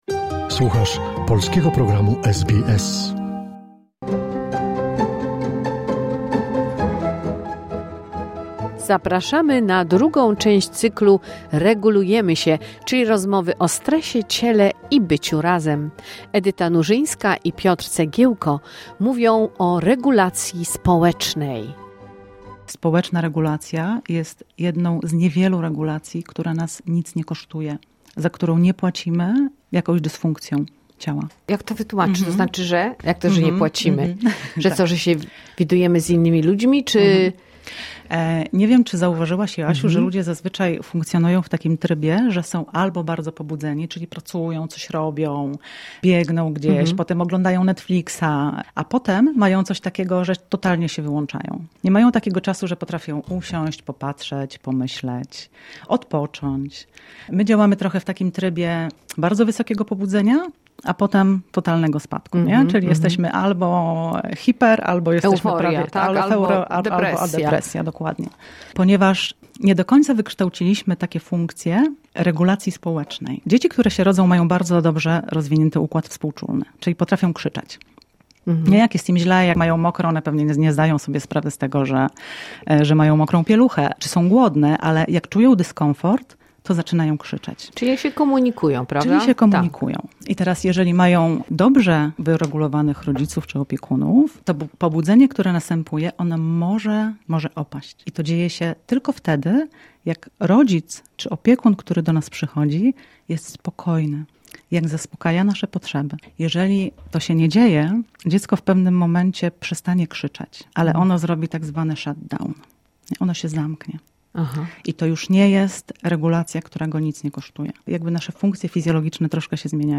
w studio SBS